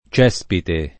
©$Spite o ©%Spite] s. m. — dal lat. caespes -itis «zolla erbosa», per vie diverse, due voci it. con -e- in principio aperta: dal nominativo, per tradiz. pop., cespo, che ha presto assunto e poi sempre mantenuto un’-e- chiusa per prob. attraz. di ceppo; e dai casi obliqui, per ricostruz. dòtta, cespite, dapprima per cinque secoli dal ’300 all’800 var. lett. o poet. dello stesso cespo, molto più tardi portato dalla campagna in città come term. econ. («fonte di reddito»), risentendo progressivam. dell’analogia di cespo nella pronunzia dell’-e-, benché non più nel significato — oggi preval. la chiusa generalm. in Tosc. (un po’ meno nei centri minori, un po’ meno nella Tosc. fior.); conservata meglio l’aperta a Roma